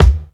KICK_SCHOOLS_OUT.wav